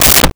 Car Door Closed 05
Car Door Closed 05.wav